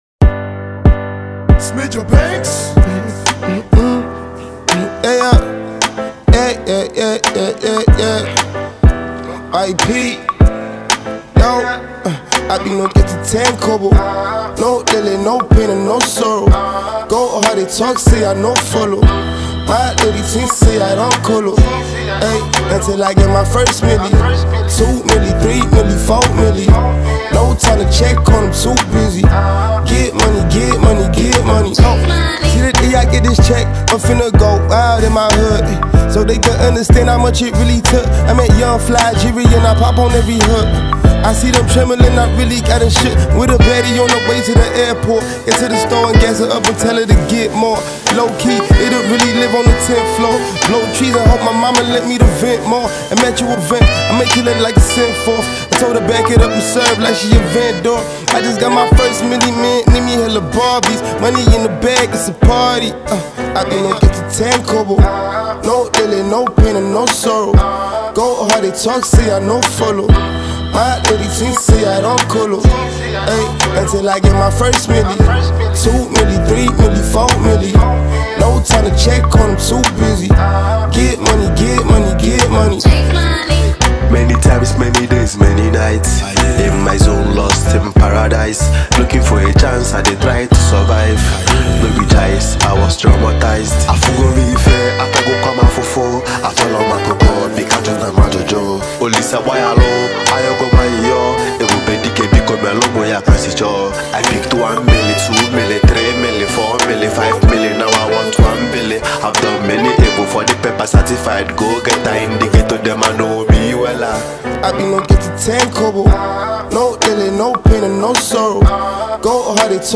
a Nigerian rapper, singer, and songwriter